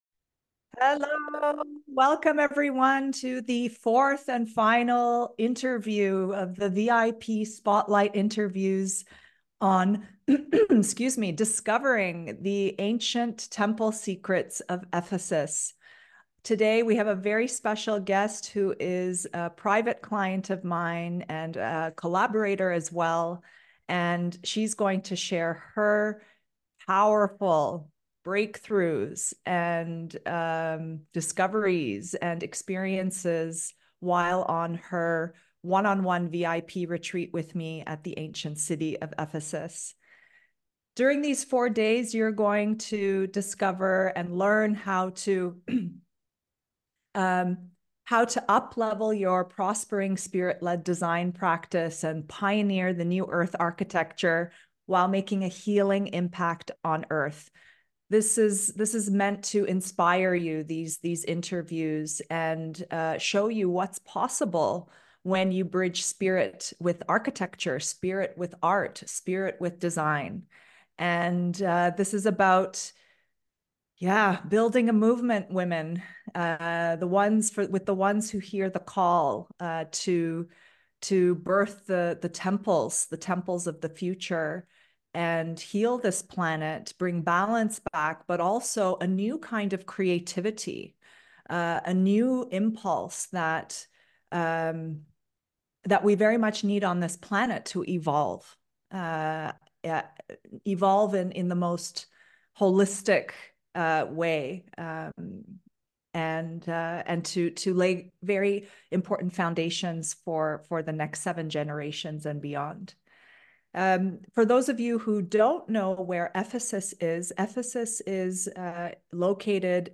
Discover Ancient Temple Secrets of Ephesus [Part 4] ~ VIP Client Spotlight Interview with metaphysical artist